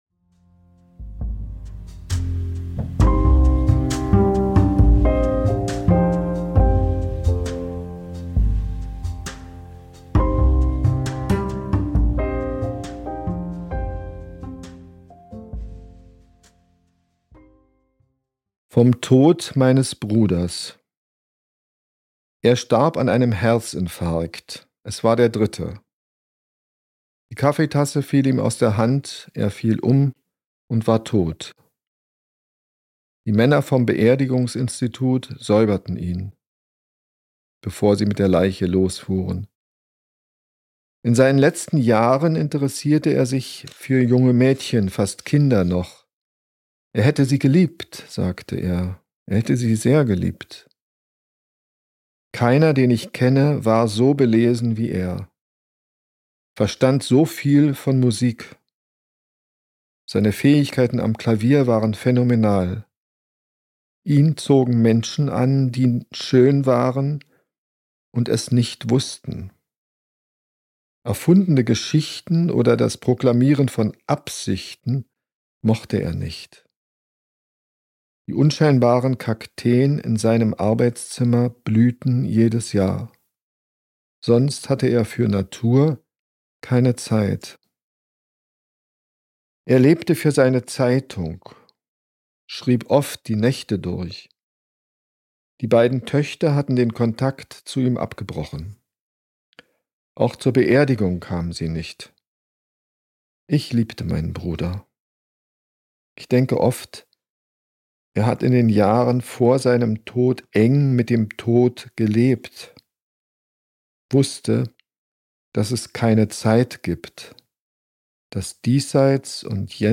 liest eigene Texte aus drei Jahrzehnten.